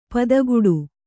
♪ padaguḍu